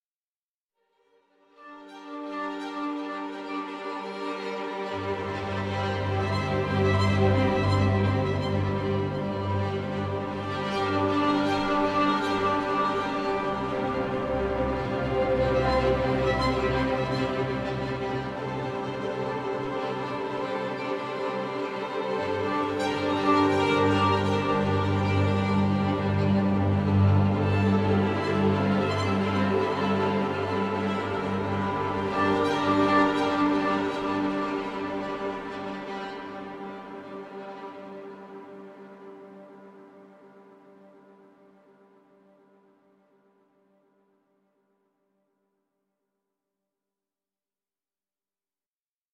- Sul Pont / Sul Tasto Textures
- 5 x mic positions (Close, High Close, Tree, Room, Wide)